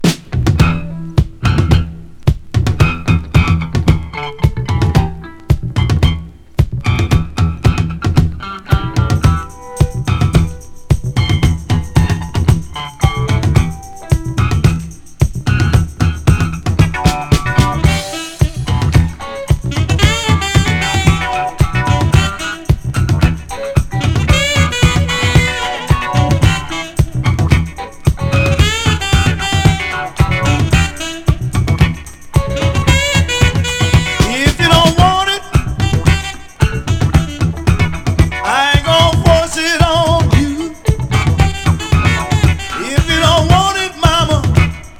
Rock, Rock'N'Roll, Funk　France　12inchレコード　33rpm　Stereo